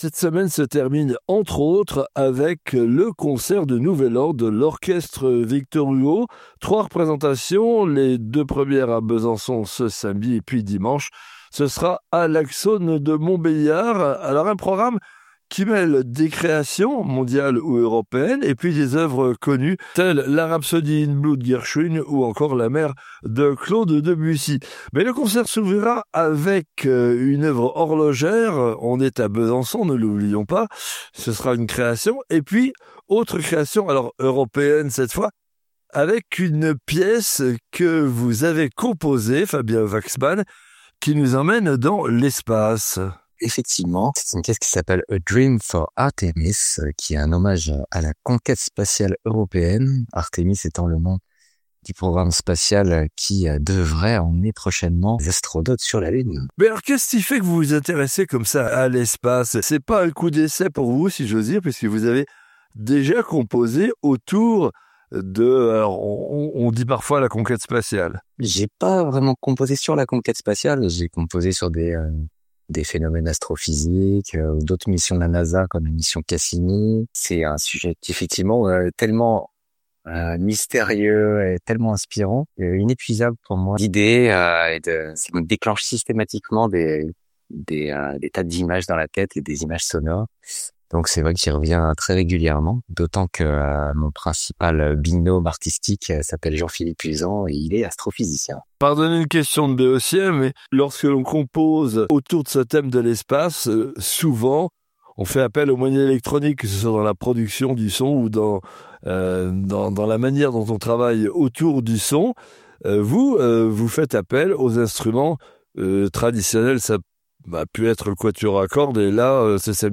concerto en quatre mouvements pour saxophones et orchestre